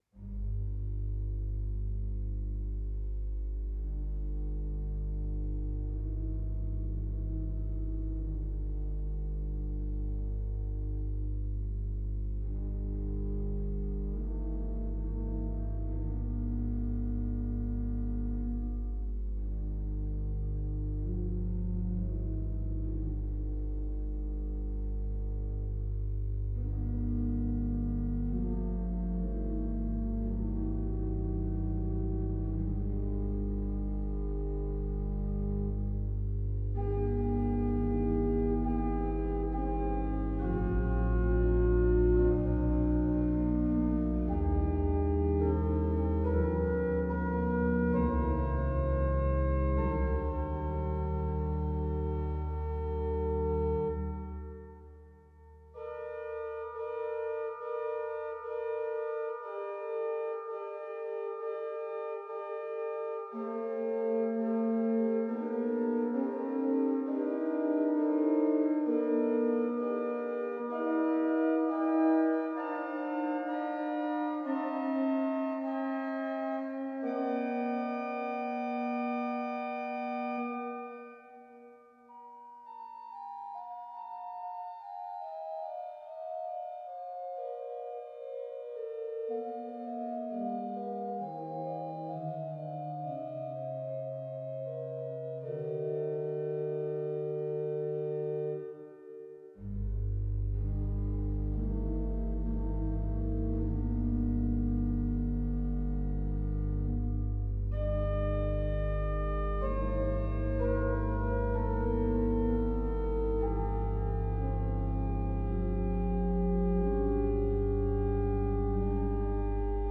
In this organ piece